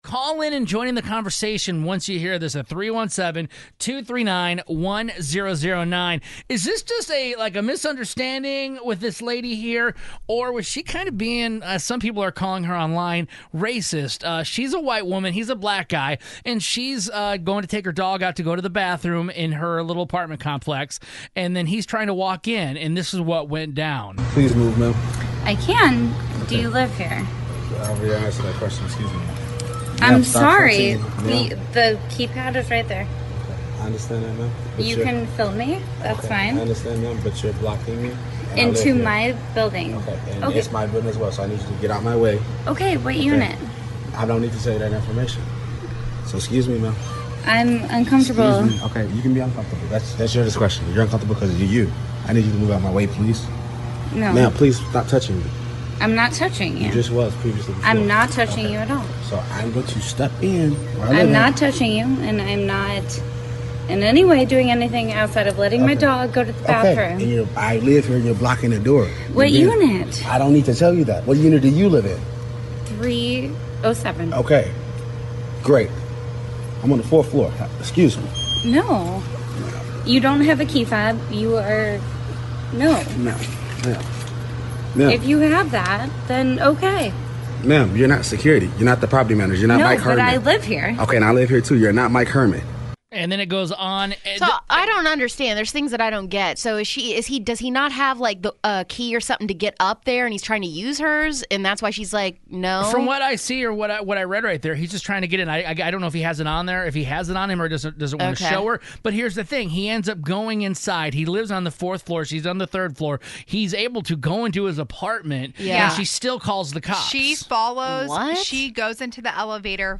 Something happened at an apartment complex in St. Louis, MI, and we discuss if we think it was racist or just a misunderstanding & have listeners call in and give their opinions.